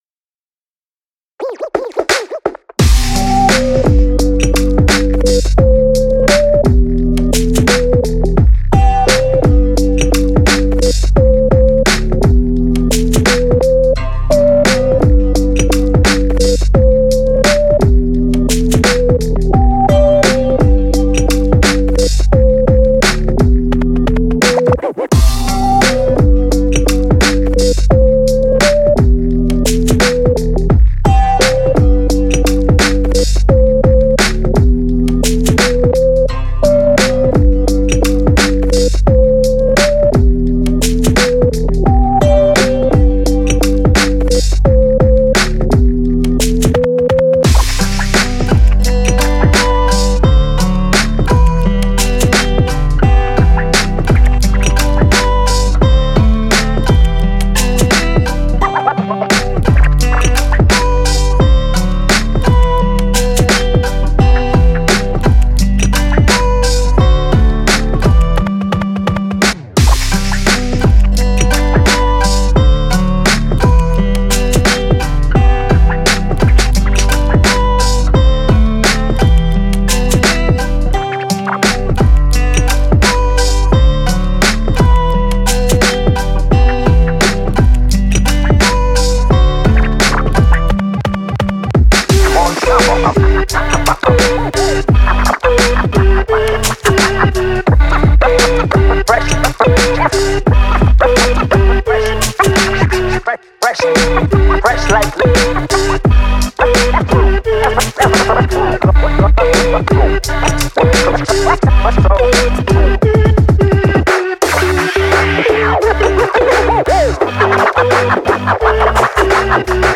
BPM86、Em/Gmaj、同じドラムセット縛りの6パターンのビート・トラックです。
EMO HIPHOP LOOP TRACK BPM86 Em/Gmaj FULL